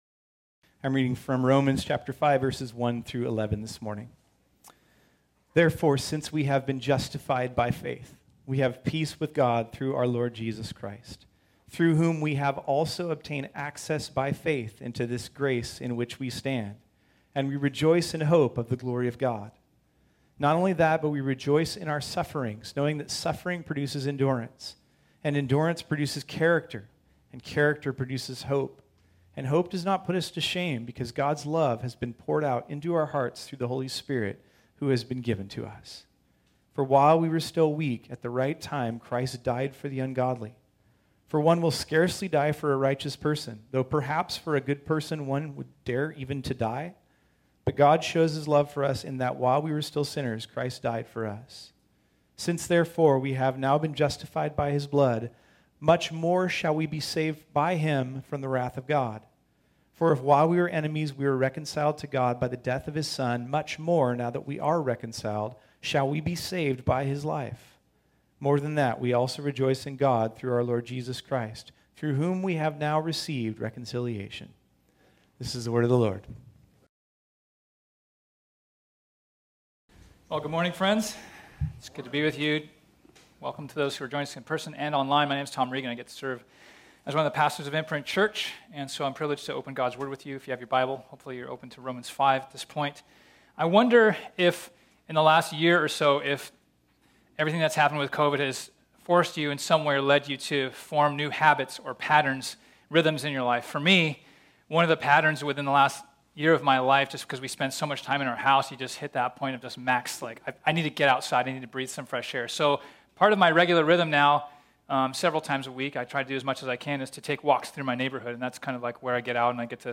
This sermon was originally preached on Sunday, March 21, 2021.